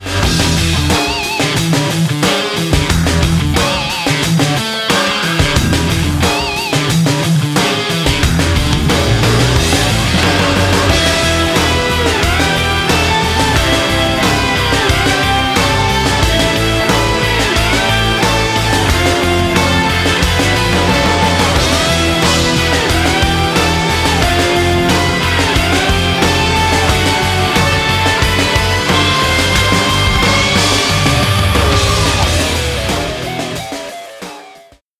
[SAMPLE]